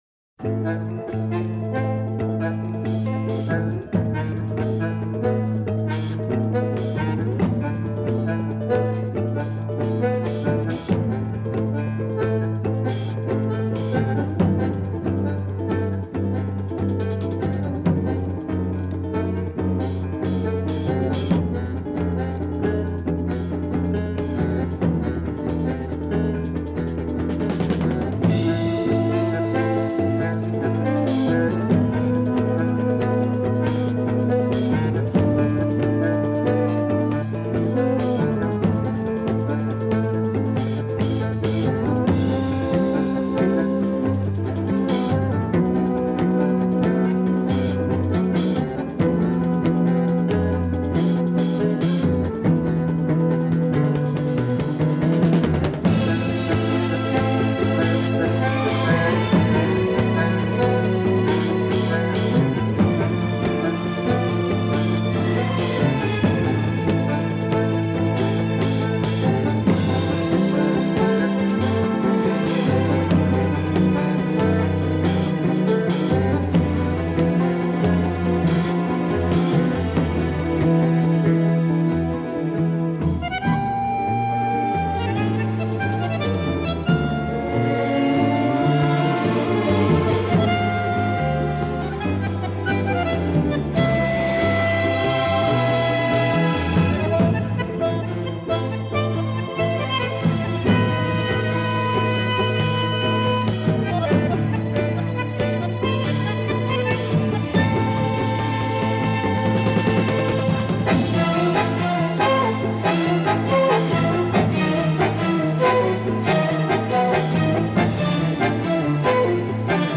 Настроение, как раз под танго...